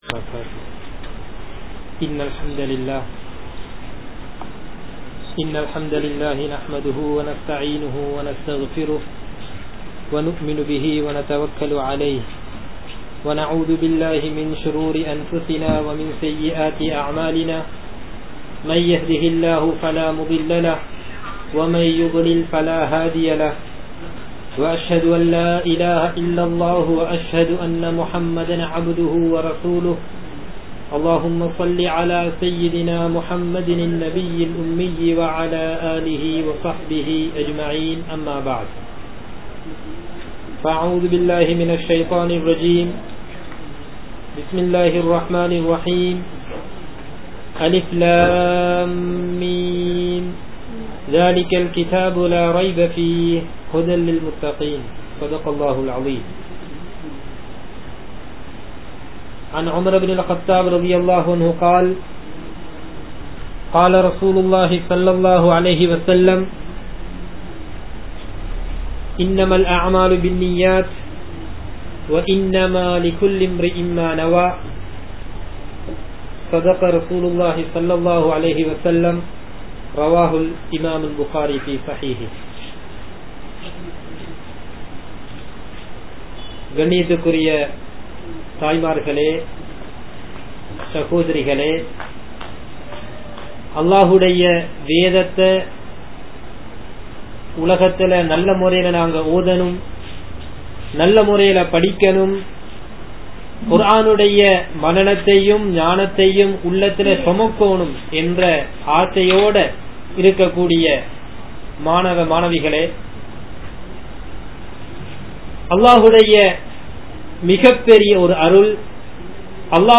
Al Quranai Sumappvarhalin Sirappu (அல்குர்ஆனை சுமப்பவர்களின் சிறப்பு) | Audio Bayans | All Ceylon Muslim Youth Community | Addalaichenai